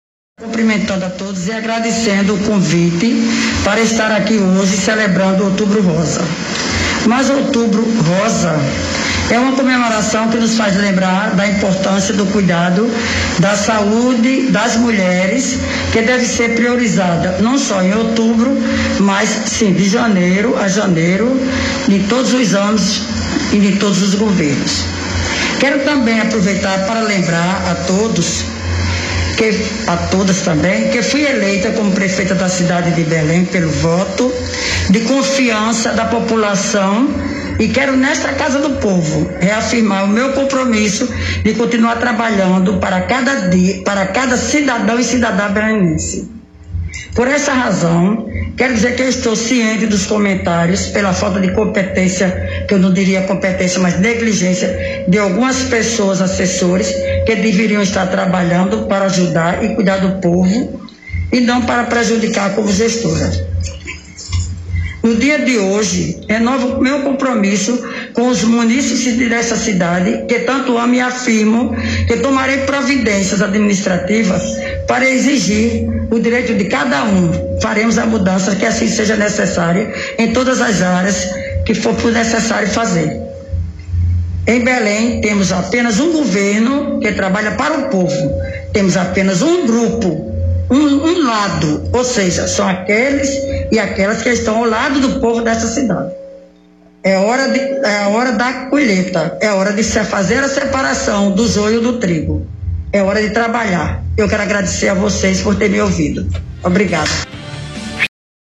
Durante o seu discurso na sessão especial nesta quarta-feira (20) em alusão ao outubro rosa, a prefeita de Belém, Aline Barbosa, surpreendeu a internautas e pessoas que se faziam presentes na Câmara Municipal ao tecer criticas pesadas aos seus assessores.